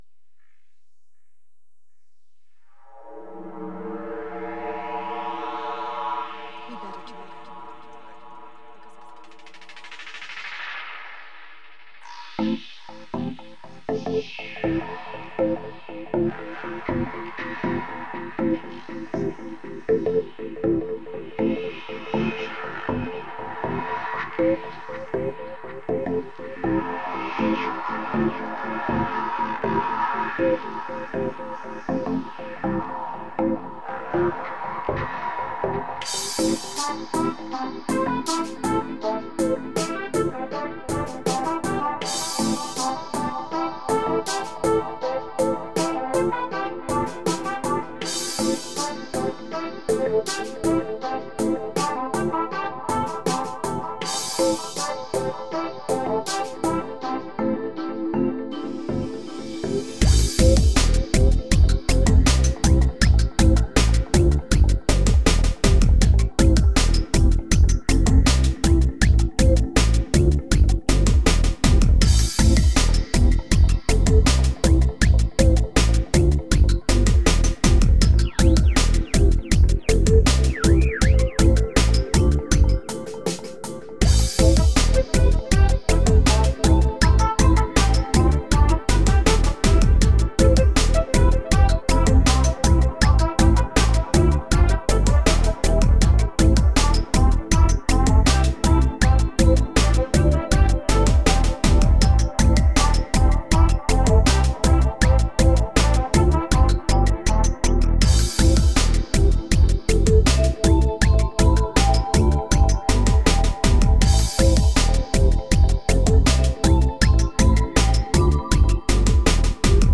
Nice intro, like the effects. But i don't think the 'whoosj' effects match good with the melody samples. Further on the song there's a very laid back bass, and the song feels like a reaggea (how do you spell that?Razz) like song.
I totally like it....gives me some nice Reggae-Feeling.
Some vocals would definately suit to it.
Yeh Smile nice one. realy soft tones in it that sounds grat dude =)